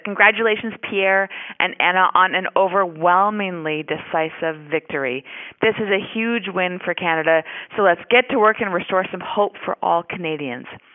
Shelby Kramp-Neuman, Conservative MP for Hastings-Lennox and Addington congratulated the new party leader on his victory.